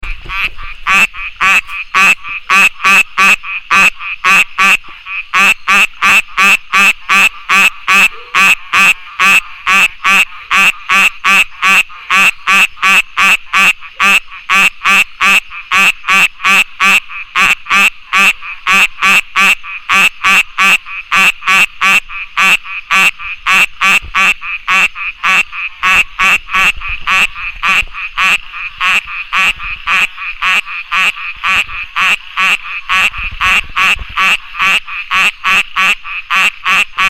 巴氏小雨蛙 Microhyla butleri
台南市 東山區 174縣道
錄音環境 次生林
10隻以上競叫